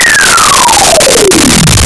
scifi12.wav